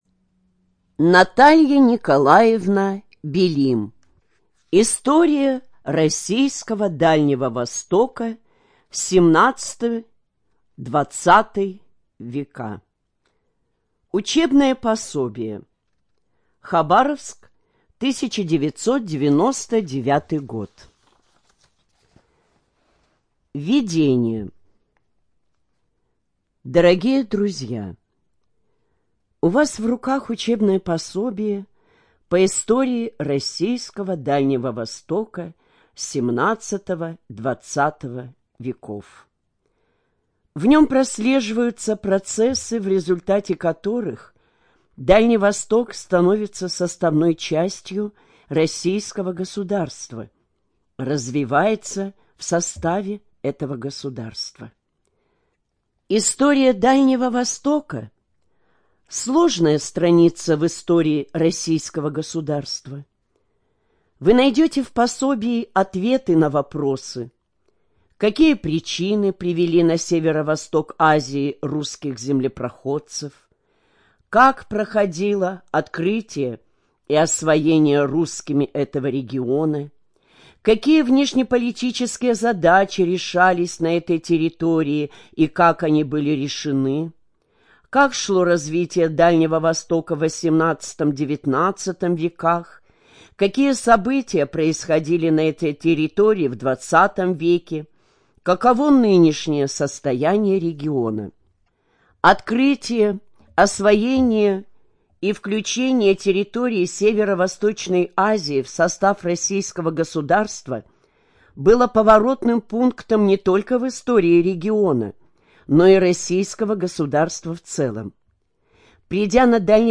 Студия звукозаписиХабаровская краевая библиотека для слепых